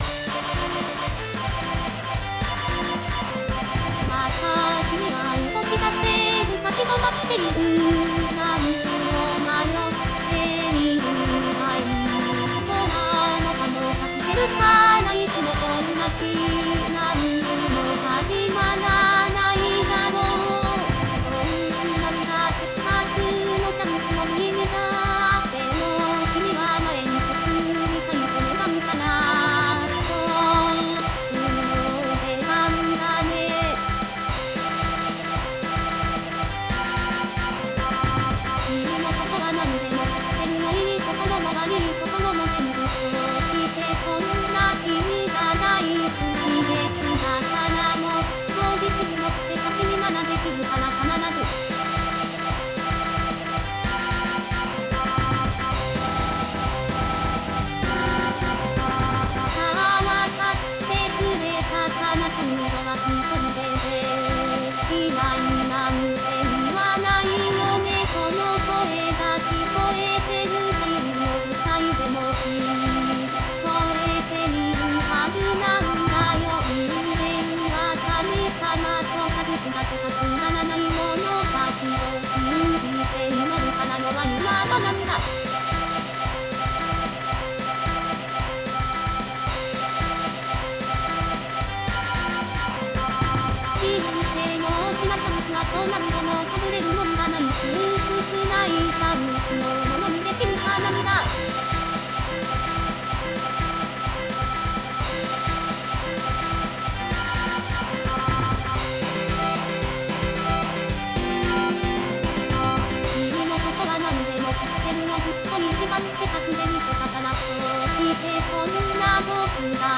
MPEG ADTS, layer III, v2, 128 kbps, 16 kHz, Monaural